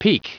Prononciation du mot peak en anglais (fichier audio)
Prononciation du mot : peak